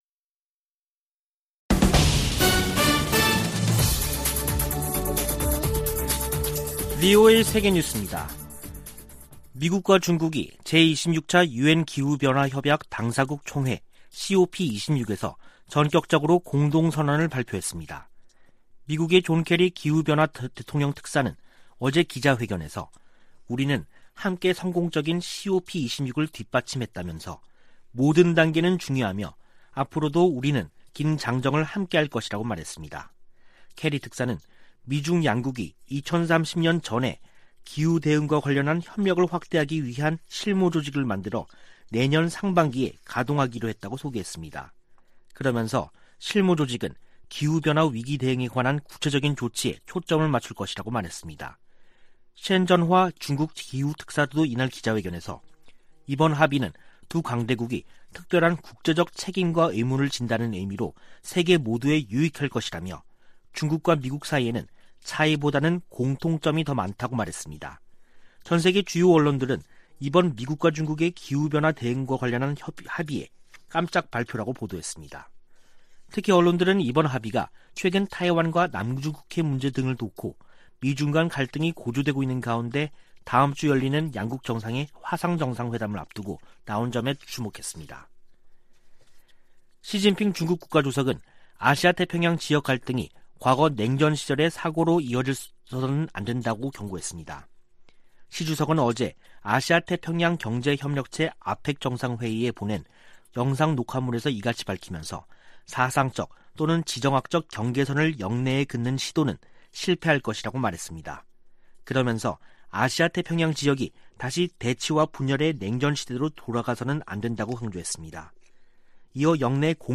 VOA 한국어 간판 뉴스 프로그램 '뉴스 투데이', 2021년 11월 11일 3부 방송입니다. 한국을 방문한 대니얼 크리튼브링크 미 국무부 동아시아태평양 담당 차관보가 여승배 한국 외교부 차관보를 만나 한반도 현안 등을 협의했습니다. 북한이 코로나 사태로 단행한 중국 국경 봉쇄를 해제할지 세계 주요 언론이 주목하고 있습니다. 북한은 세계 176개국 가운데 자연재해 노출 정도가 두 번째로 심각하다고 이탈리아에 본부를 둔 비정부기구가 밝혔습니다.